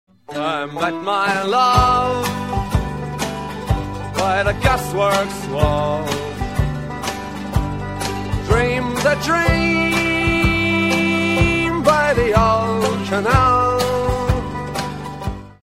Despite the Irish accent it's fairly 'typical' singing